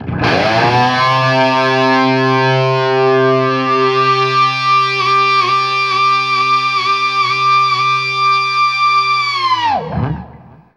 DIVEBOMB 3-L.wav